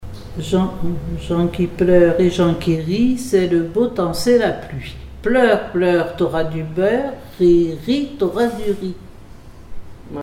Comptines et formulettes enfantines
Pièce musicale inédite